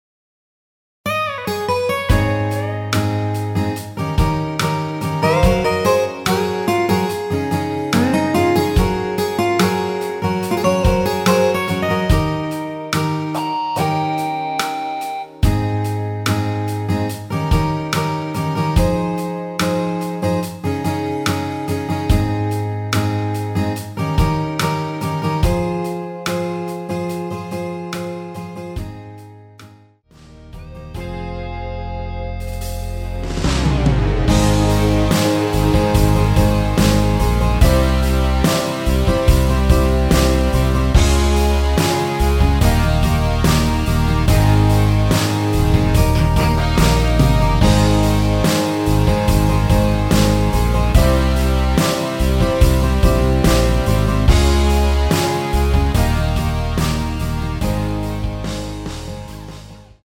대부분의 여성분이 부르실수 있는 키로 제작 하였습니다.
Ab
앞부분30초, 뒷부분30초씩 편집해서 올려 드리고 있습니다.
중간에 음이 끈어지고 다시 나오는 이유는